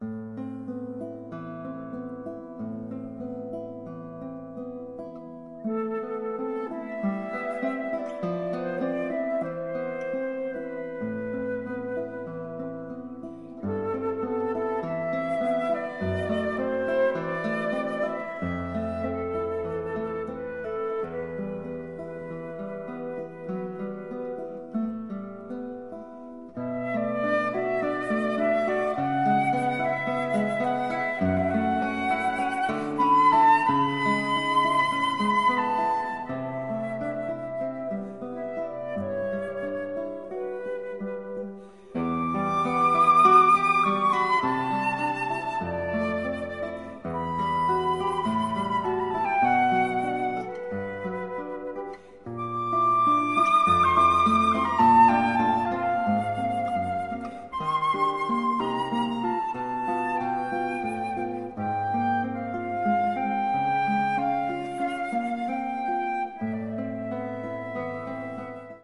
flute
guitar